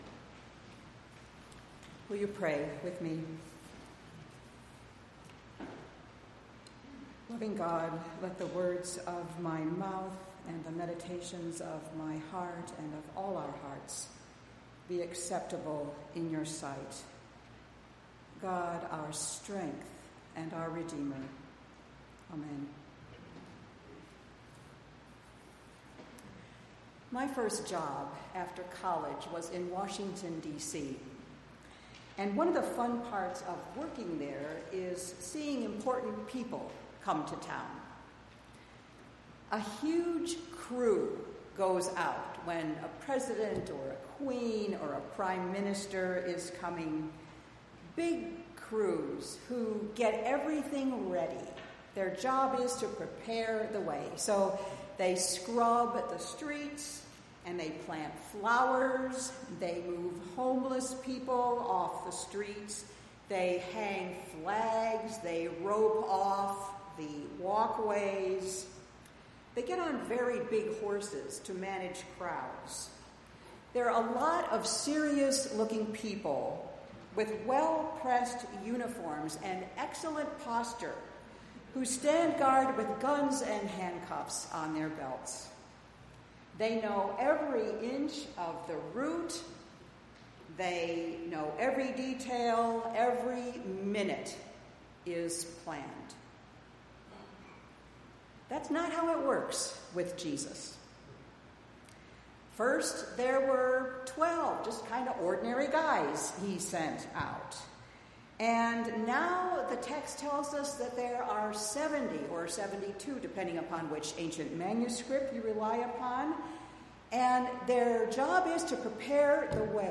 7-7-19-sermon.mp3